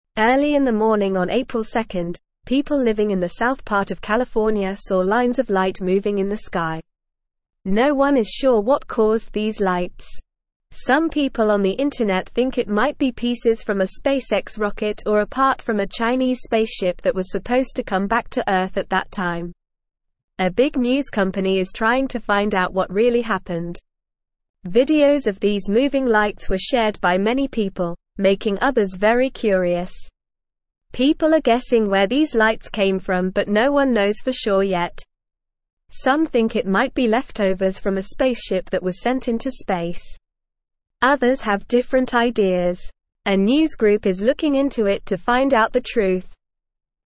Normal
English-Newsroom-Beginner-NORMAL-Reading-Mysterious-Lights-in-California-Sky-What-Happened.mp3